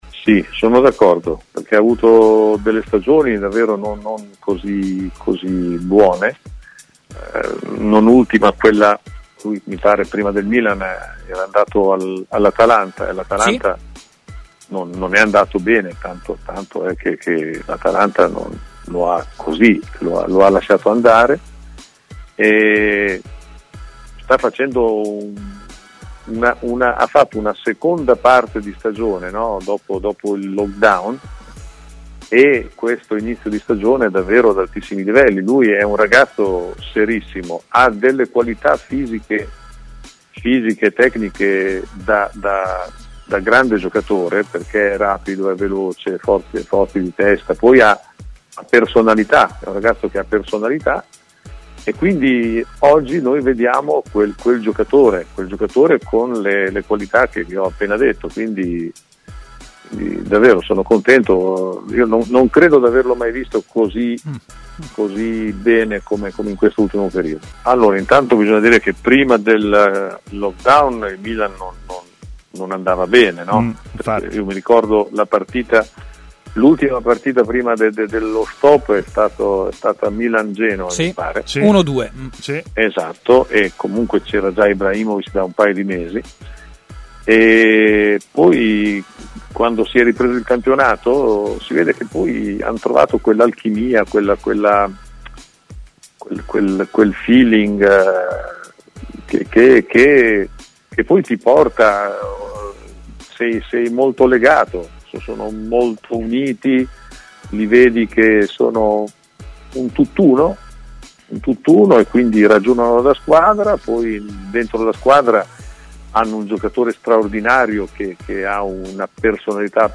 Intervenuto ai microfoni di TMW Radio, l'ex allenatore Davide Ballardini ha parlato del Milan e di Simon Kjaer.